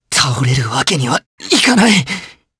Theo-Vox_Dead_jp.wav